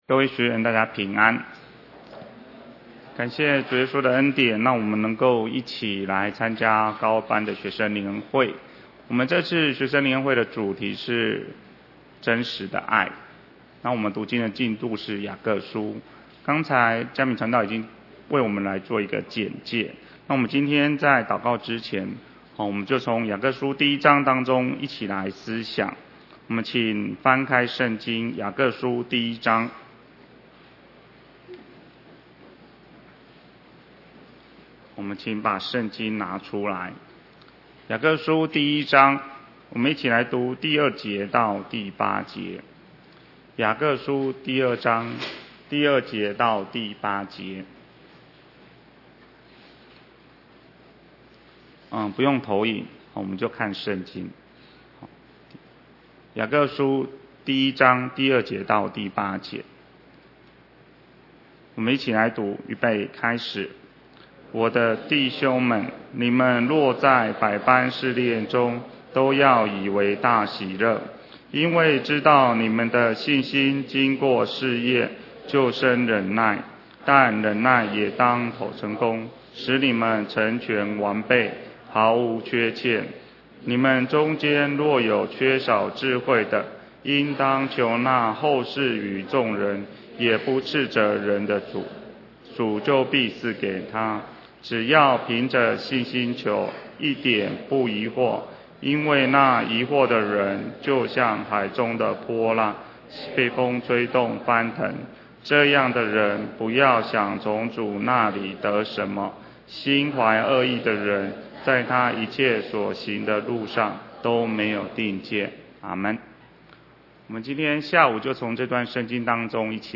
2016年7月3~7日 北區高二班學生靈恩會（主題：真實的愛）課程錄音